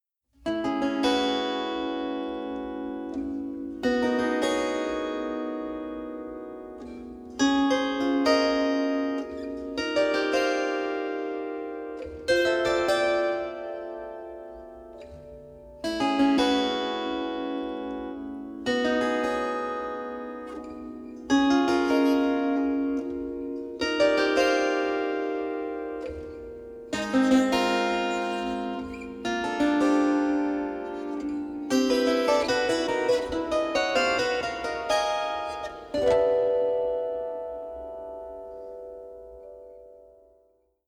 36-string Double Contraguitar, 30-string Contra-Alto guitar